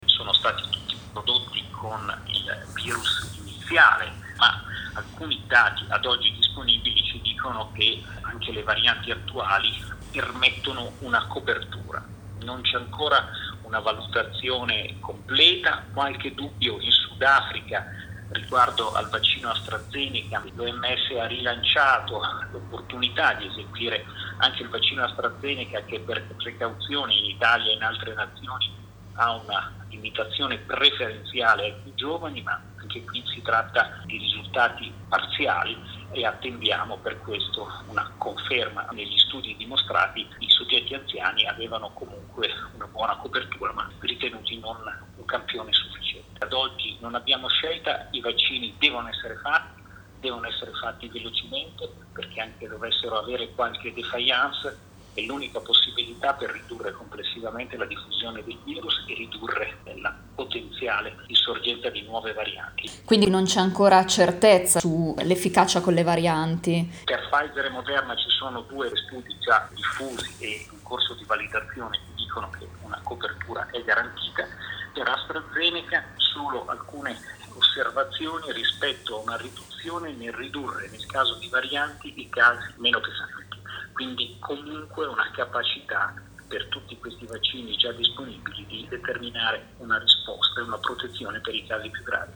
Abbiamo chiesto al virologo e membro del Cts lombardo, Fabrizio Pregliasco, se i vaccini in circolazione sono efficaci anche con queste varianti.